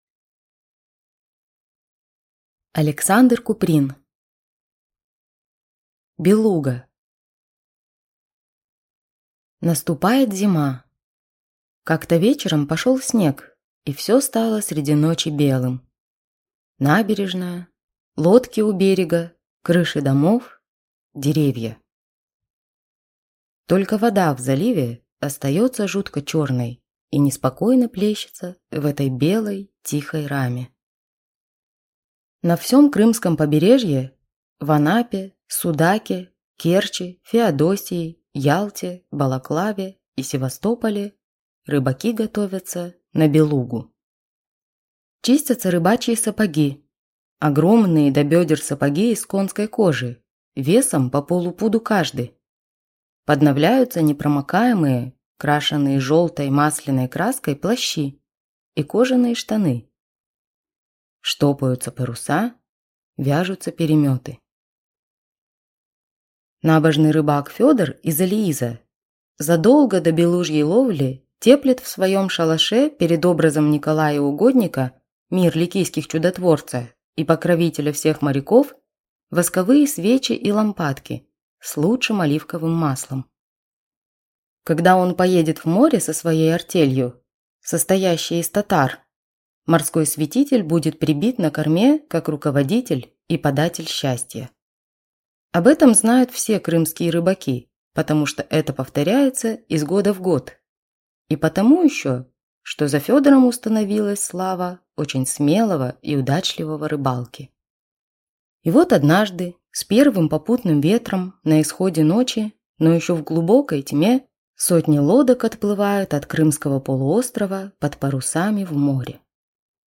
Aудиокнига Белуга